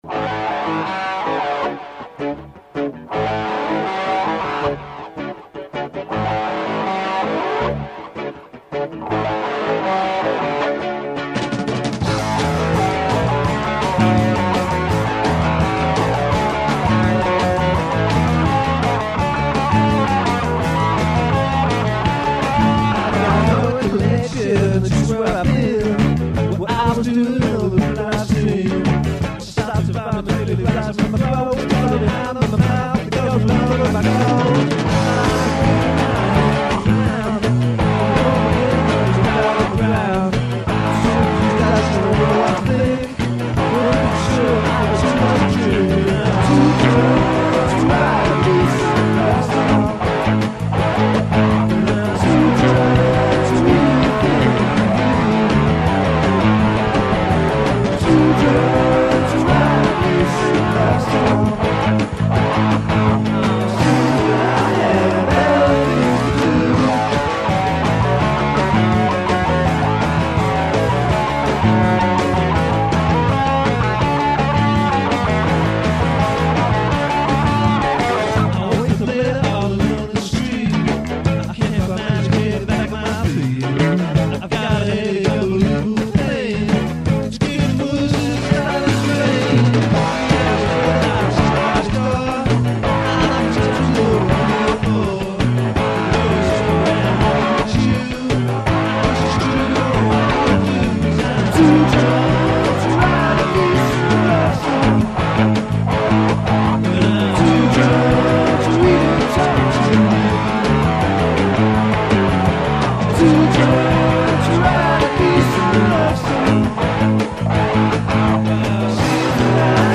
dance/electronic
Punk
Rock & Roll
Indy